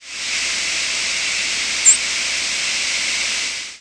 Fox Sparrow nocturnal
presumed Fox Sparrow nocturnal flight calls